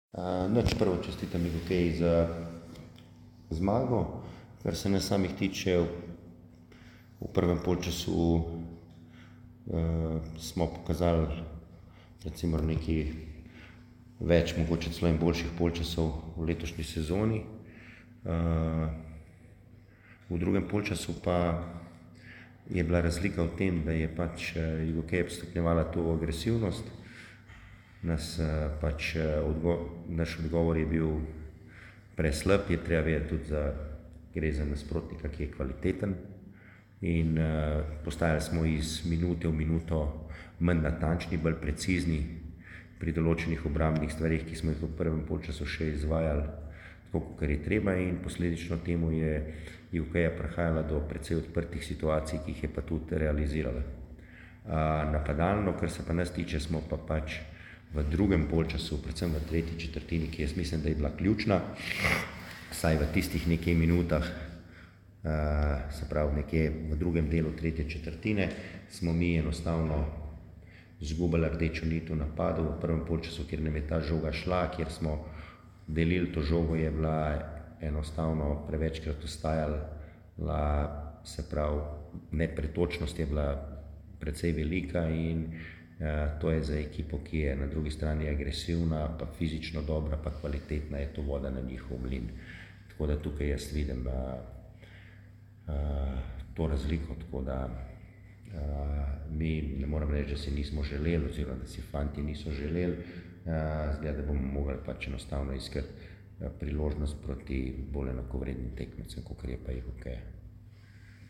Izjavi po tekmi: